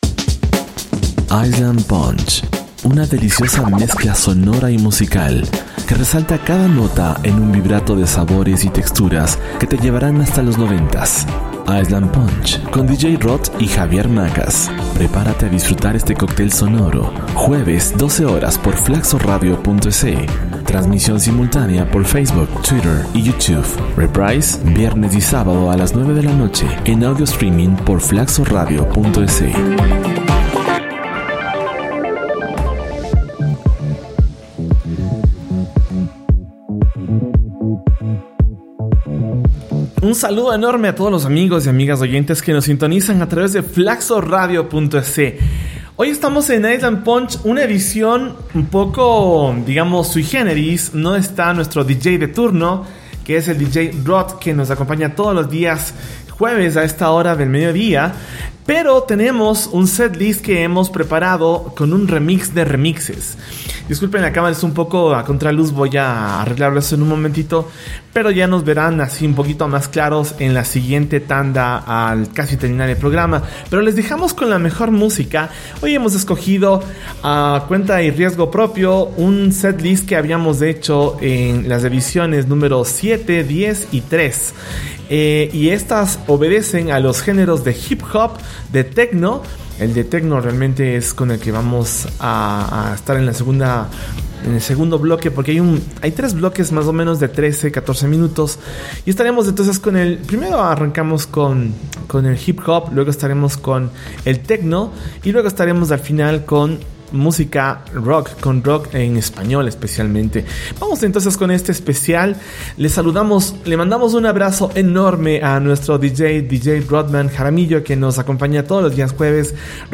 Este es un super remix con música de los 90tas.